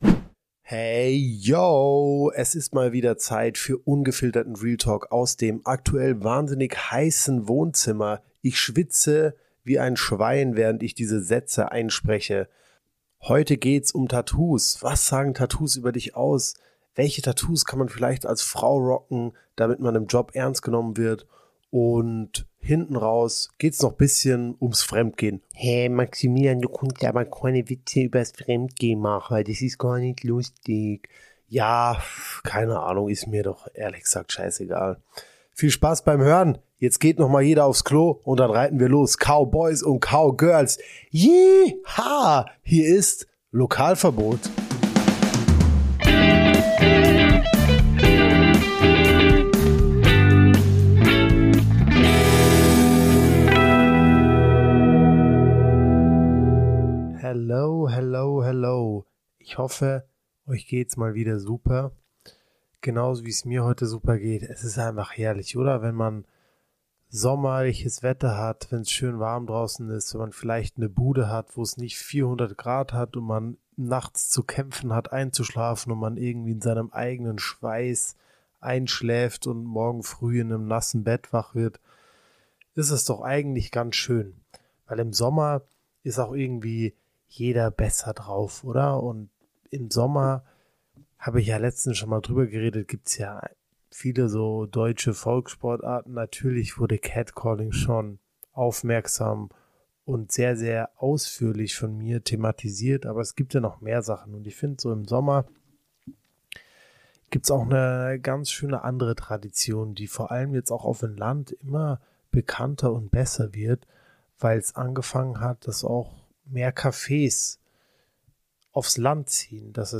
#033 Tattoolästerei straight aus dem Café ~ Lokalverbot Podcast